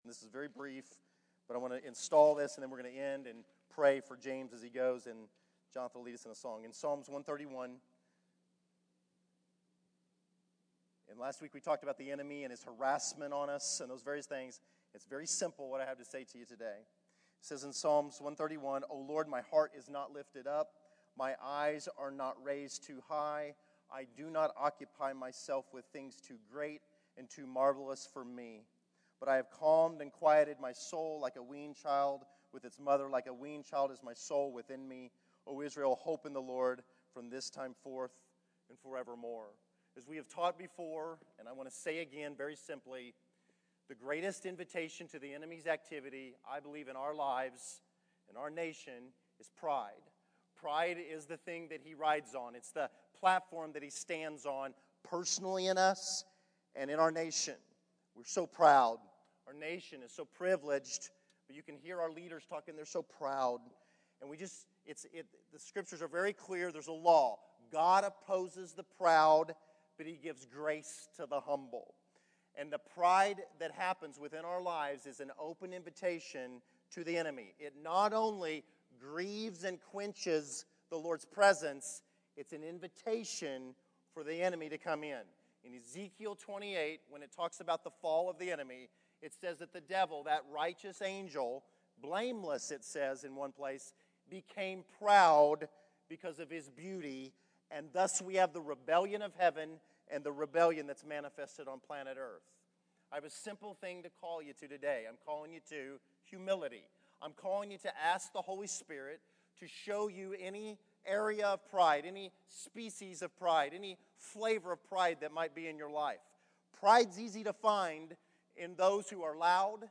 Category: Sermons | Location: El Dorado